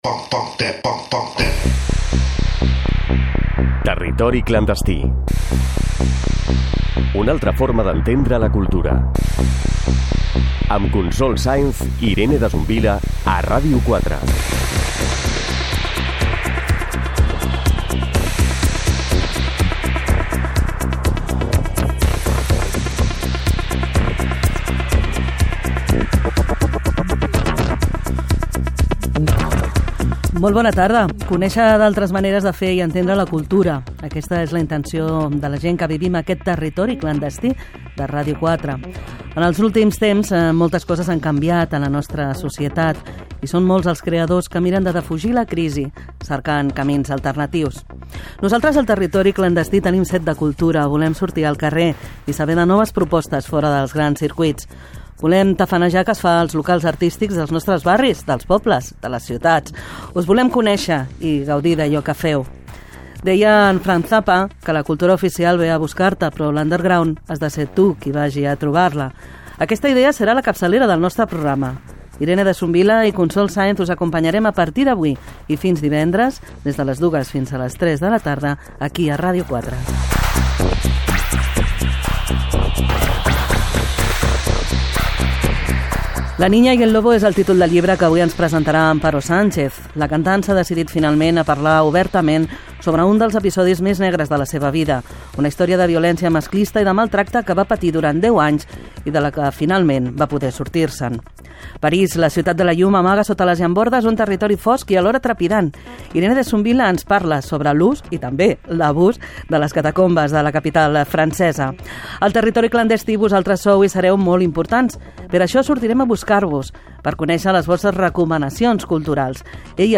Identificació del programa, presentació de la primera edició del programa amb els seus objectius, sumari de continguts, entrevista a la cantant Amparo Sánchez que presenta un disc i un llibre
Cultura